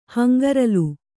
♪ hangaralu